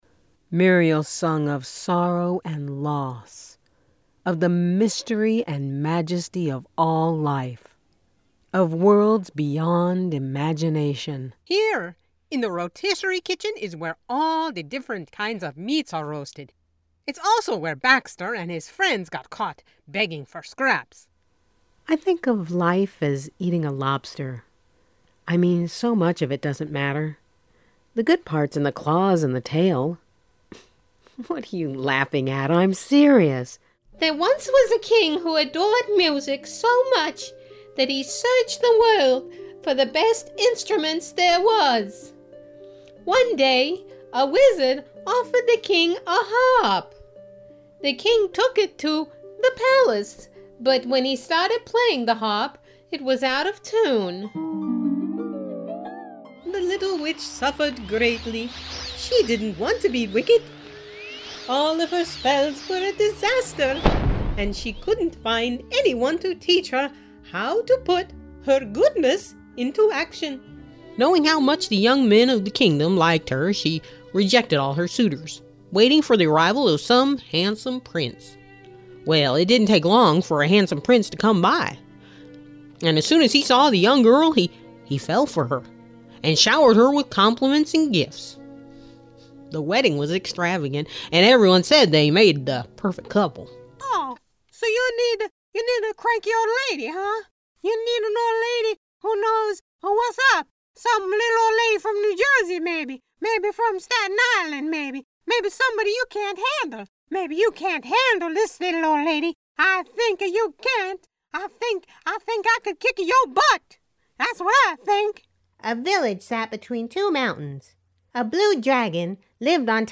Strong Women
Middle Aged